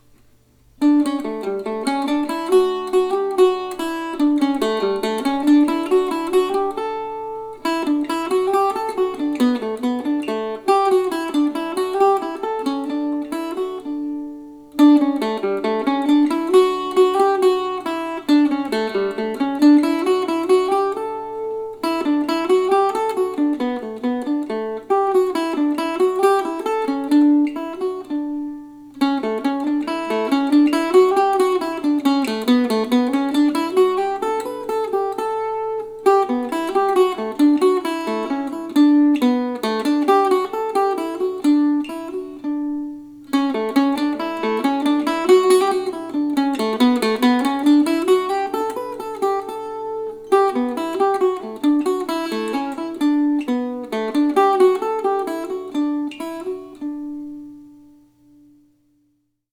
for mandocello or octave mandolin.